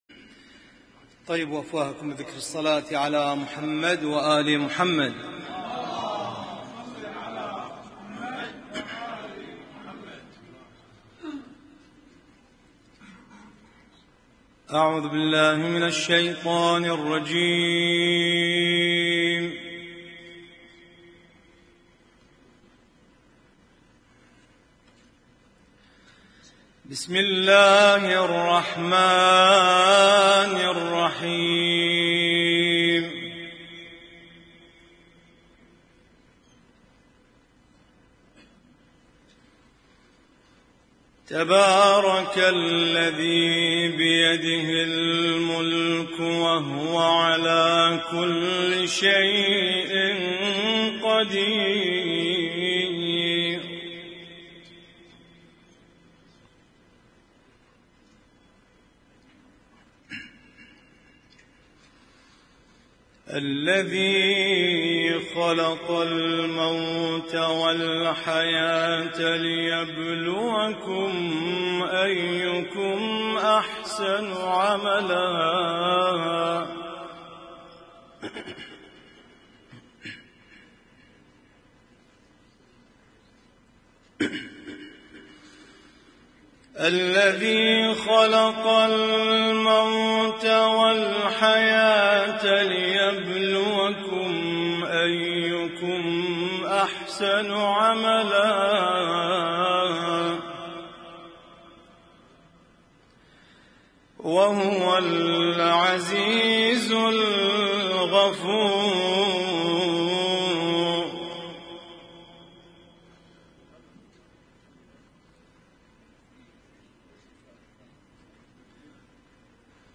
اسم النشيد:: ما تيسر من القرآن الكريم من سورة الملك طورعراقي
اسم التصنيف: المـكتبة الصــوتيه >> القرآن الكريم >> القرآن الكريم - القراءات المتنوعة